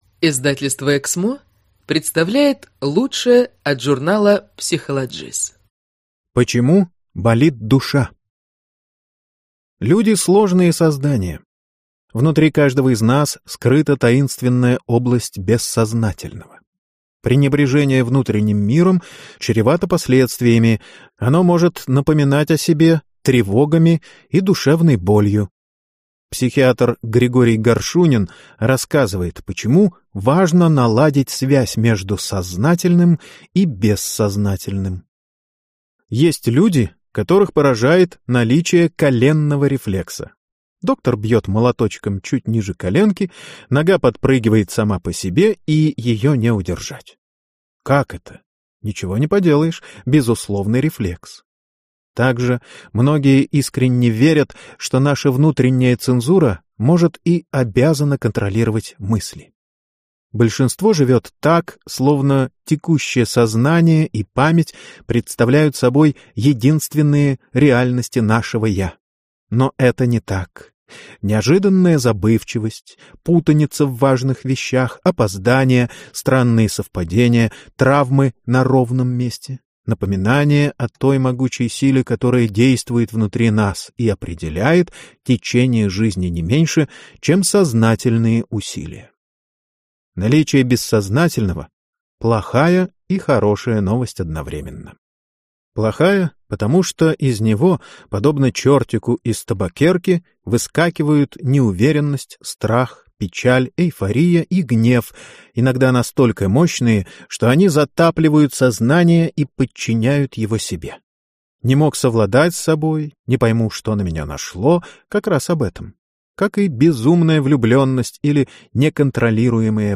Аудиокнига Остановить мгновенье: секреты осознанной жизни | Библиотека аудиокниг
Прослушать и бесплатно скачать фрагмент аудиокниги